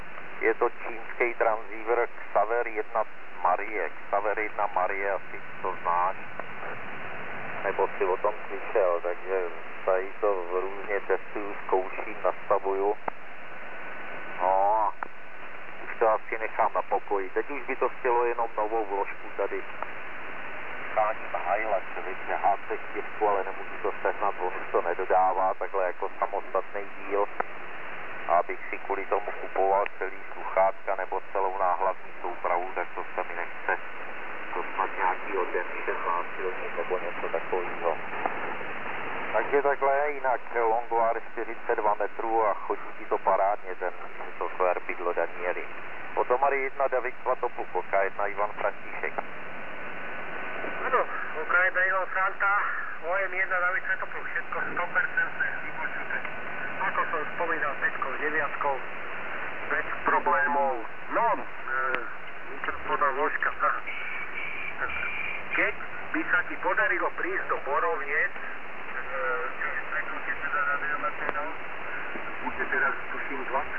Po zakl�čov�n� dojde ke zkr�cen� prvn� značky a "zakviknut�" Chyb� ATT Chyb� AGC Chyb� regulace v�konu Špatn� modulace Kliksy PA stupeň je nastaven snad do tř�dy C (určitě nen� line�rn�).